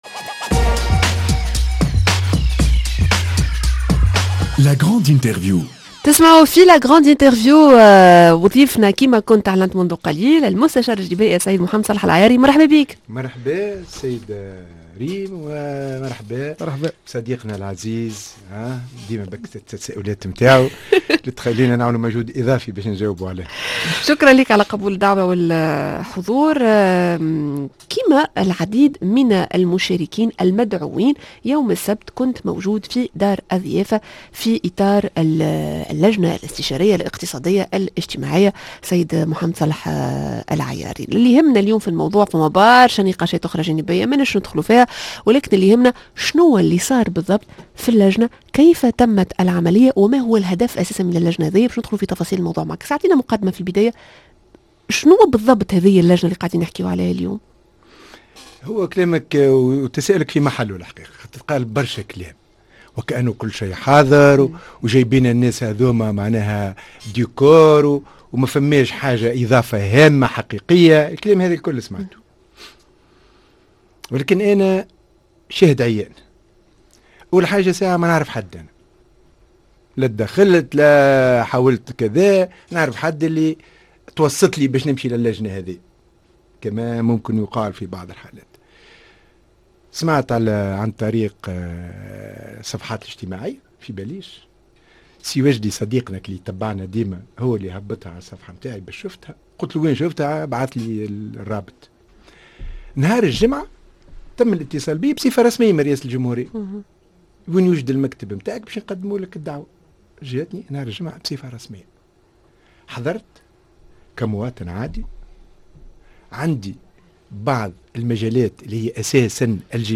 L'interview: ماهي الخطوط الكبرى للاصلاح الجبائي؟